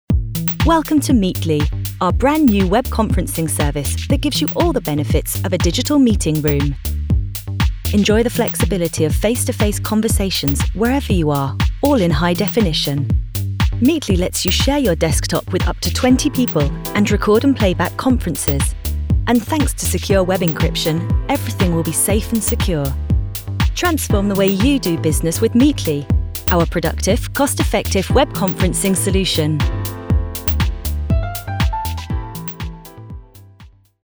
Anglais (Britannique)
Commerciale, Jeune, Naturelle, Amicale, Corporative
Vidéo explicative